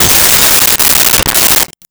Window Shatter 03
Window Shatter 03.wav